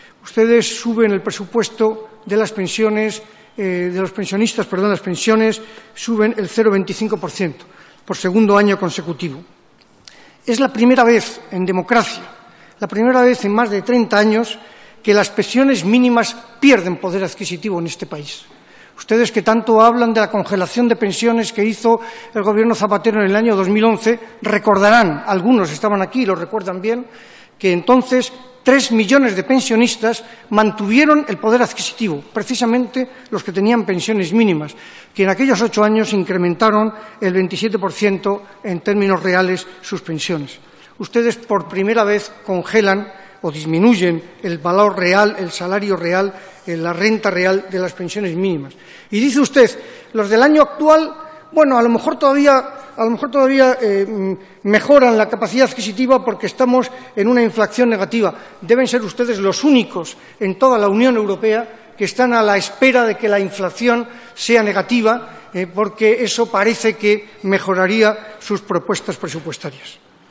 Fragmento de la intervención de Manuel de la Rocha en la Comisión de Empleo y Seguridad Social.